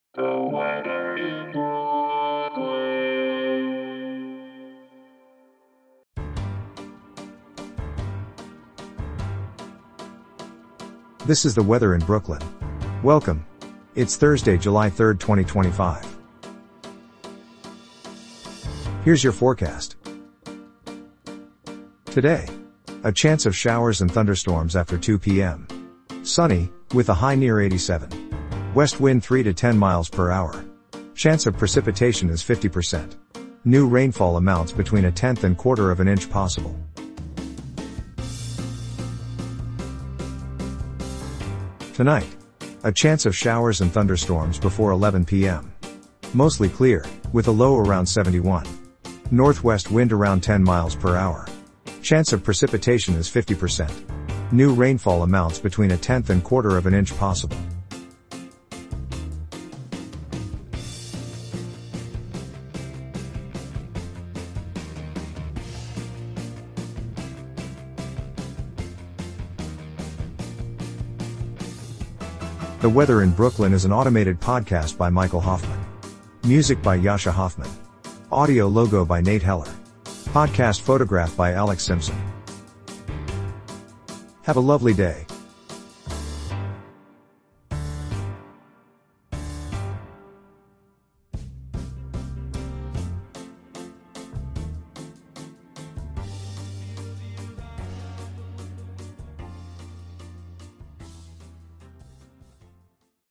is generated automatically.